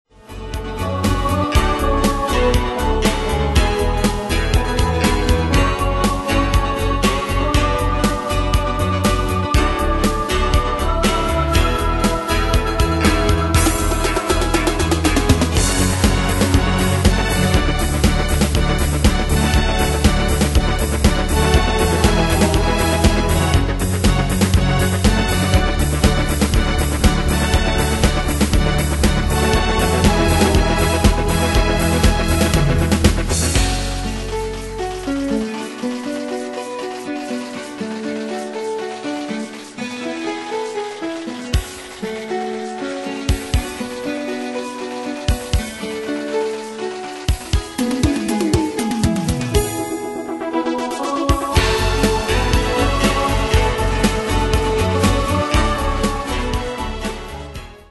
Demos Midi Audio
Danse/Dance: Rock Cat Id.
Pro Backing Tracks